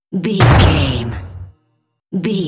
Cinematic drum hit trailer
Sound Effects
Atonal
heavy
intense
dark
aggressive
hits